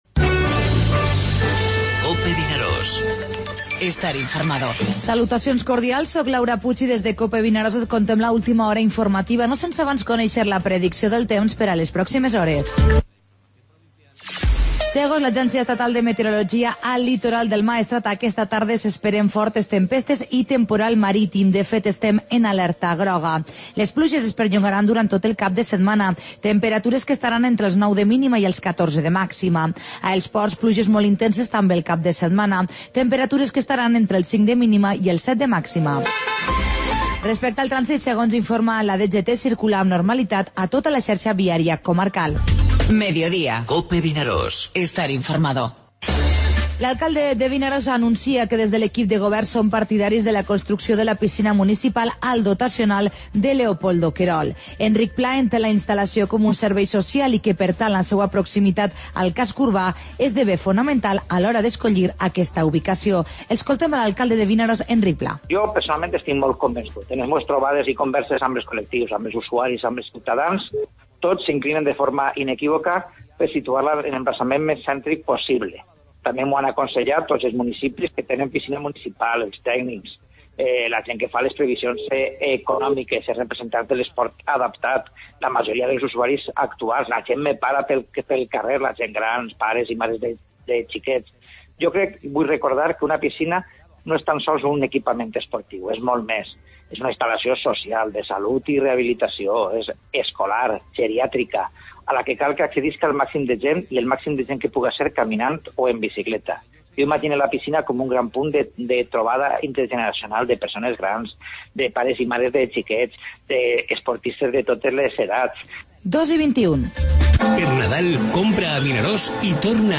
Informativo Mediodía COPE al Maestrat (divendres 16 de desembre)